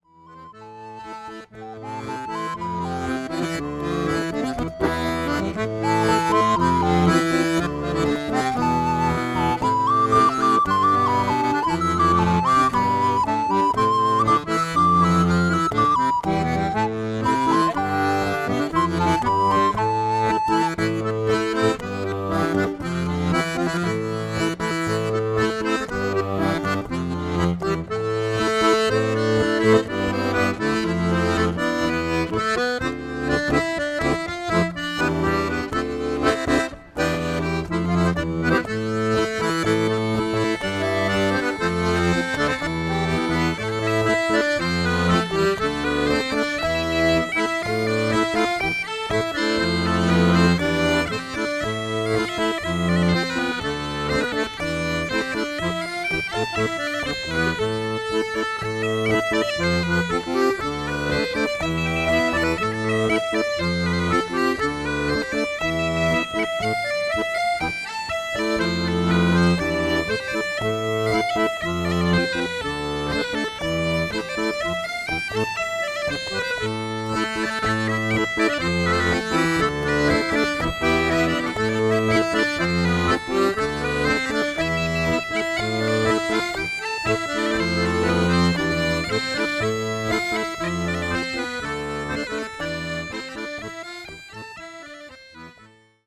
(RECORDED  ENREGISTREMENT LIVE):